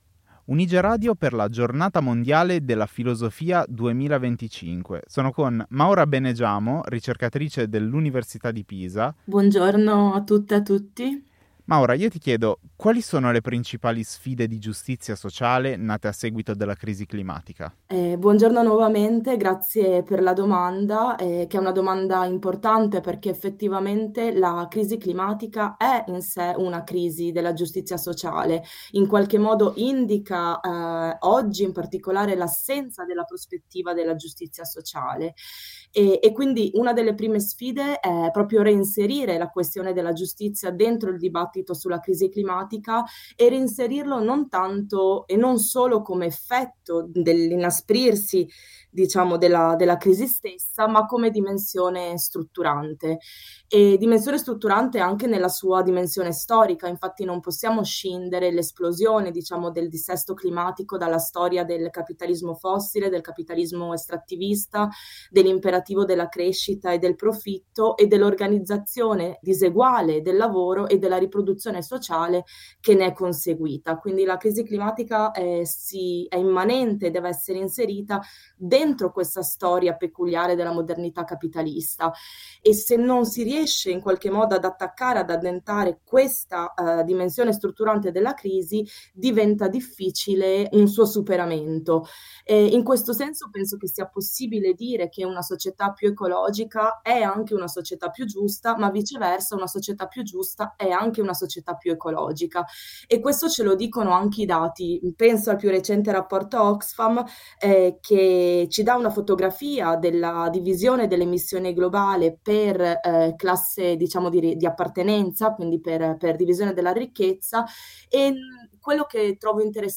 Un dialogo che mostra perché una società più ecologica non può che essere anche una società più giusta.